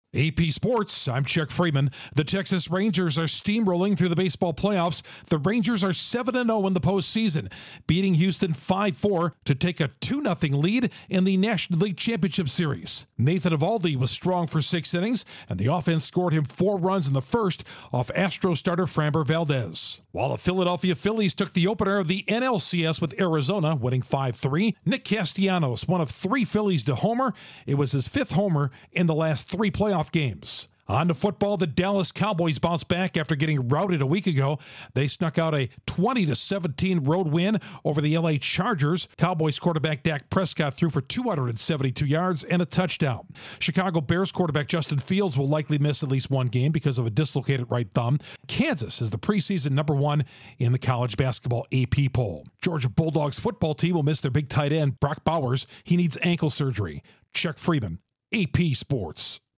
The Rangers remain unbeaten in the MLB post season, the Phillies win Game 1 of the NLCS, the Cowboys bounce back, and Kansas dominates the rankings in college basketball. Correspondent